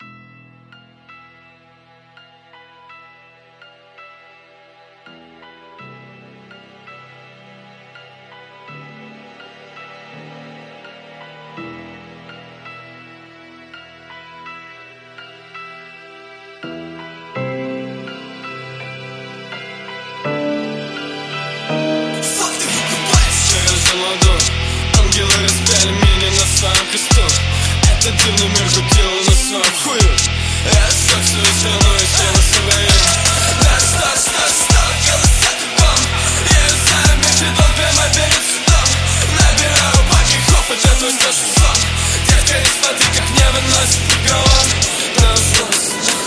Тренирую сведение непонятного мне по жанру трека, здесь куча инструментов и исходник вокала писанный на AT2020 без акустики.
Сразу говорю тут на мастере до 20 и от 20000 срезано, bx_digital в моно все до 200 впихивает, и c6 слегка высоких поднял, но стало уши резать.